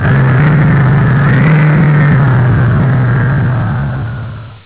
swampmonster.wav